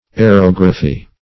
Aerography \A`["e]r*og"ra*phy\, n. [A["e]ro- + -graphy: cf. F.